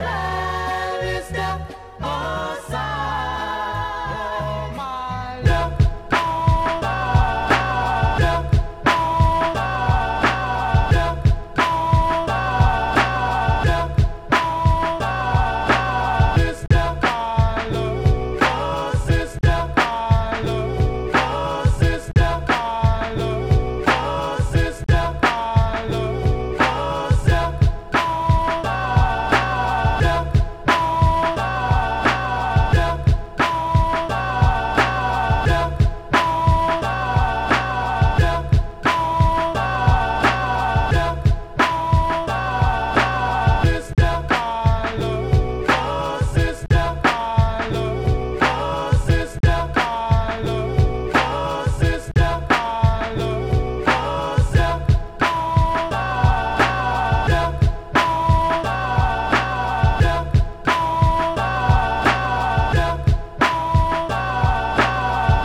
Ich habe mal ein rohes Klangbeispiel angehängt. Auch die Drums sind noch roh, aber mir gehts primär um die gechoppten Samples...
Anhänge Iris 88 BPM Skizze Rough.wav Iris 88 BPM Skizze Rough.wav 18 MB